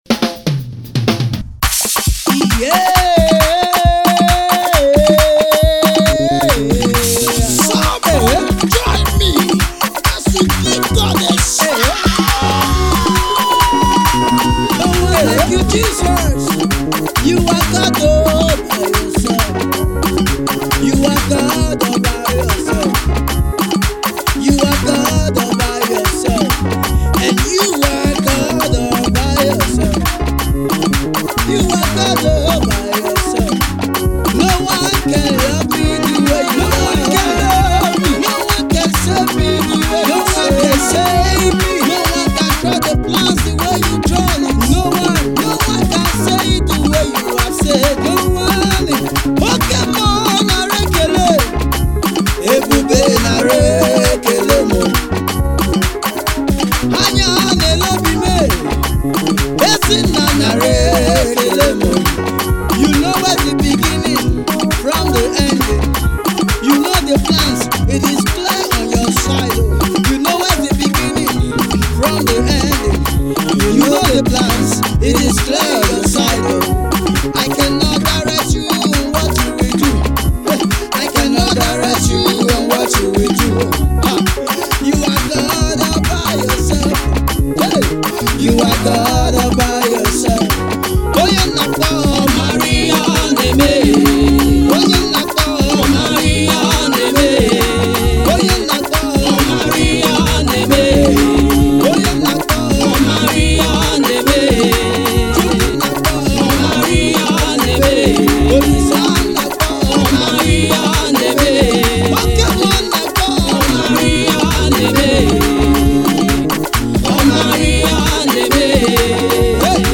praise